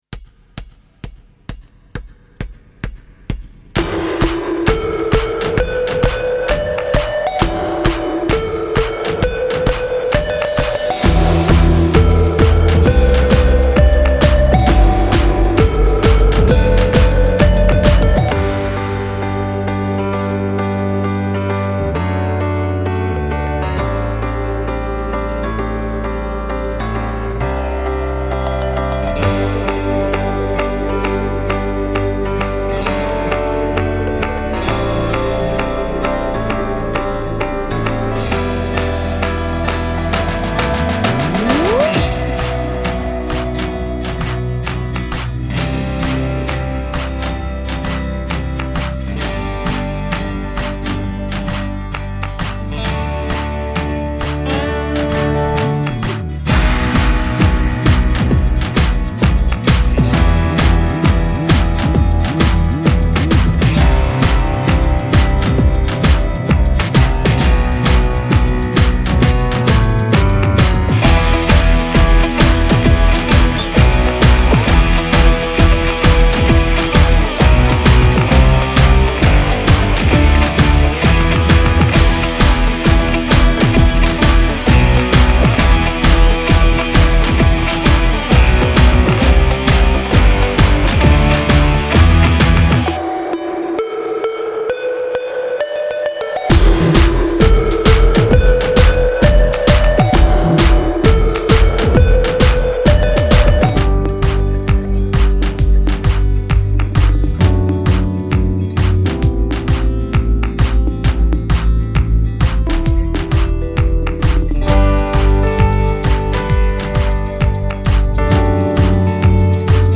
Base musical sin letra